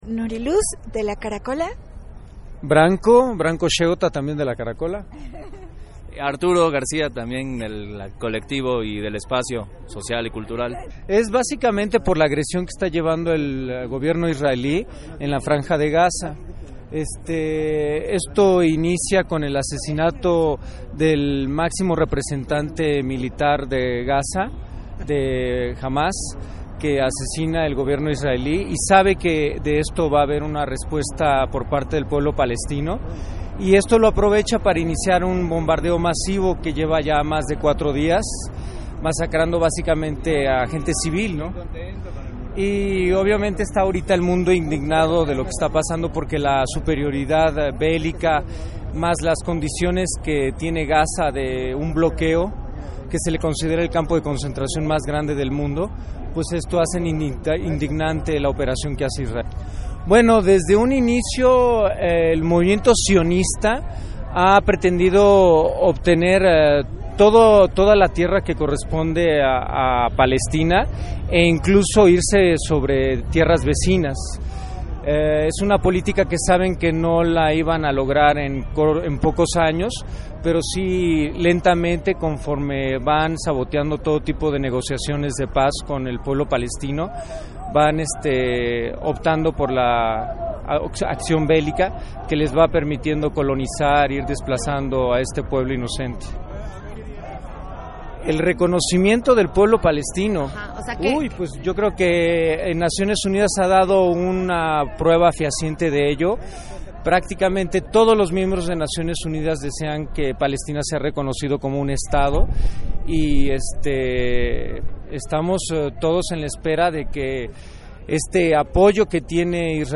Entrevista Colectivo Karakola Global